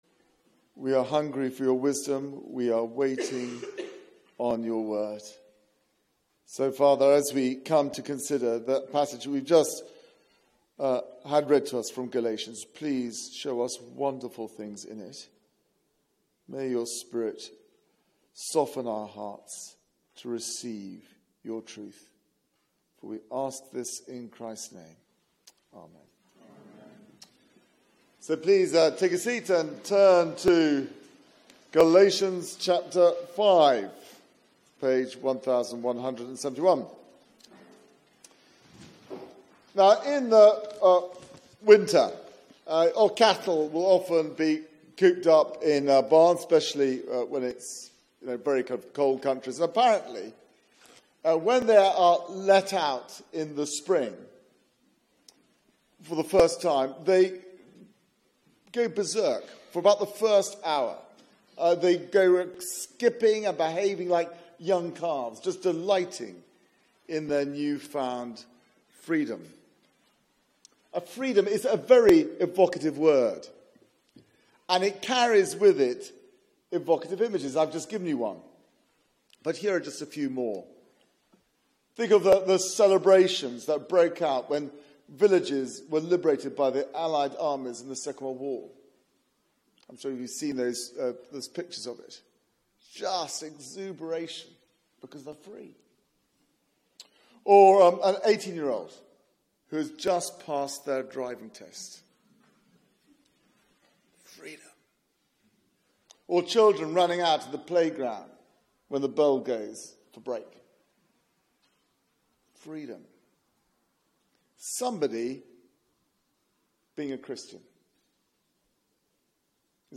Media for 6:30pm Service on Sun 29th Oct 2017 18:30 Speaker
Theme: Knocked off course Sermon